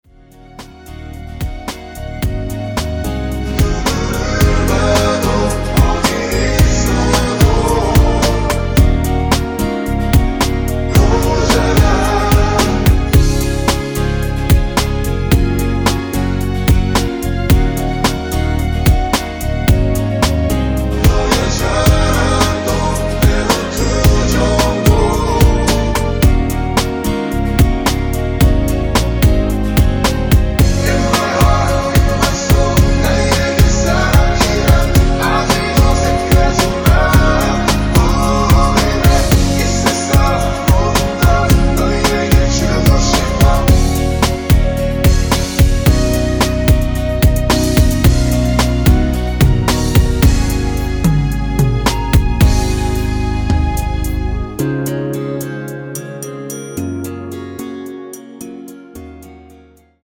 원키에서(-2)내린 멜로디와 코러스 포함된 MR입니다.(미리듣기 확인)
앞부분30초, 뒷부분30초씩 편집해서 올려 드리고 있습니다.
중간에 음이 끈어지고 다시 나오는 이유는